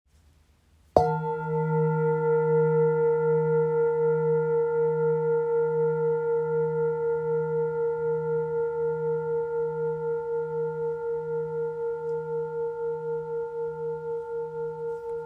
Etched Medicine Buddha Singing Bowl – 23cm
This handcrafted Himalayan singing bowl is beautifully etched with the image of the Medicine Buddha, the embodiment of healing, compassion, and restoration.
When struck or sung, it produces clear, harmonious tones that resonate deeply, creating a space for balance, renewal, and inner peace.
The bowl measures 23cm in diameter.